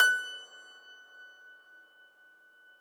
53k-pno20-F4.wav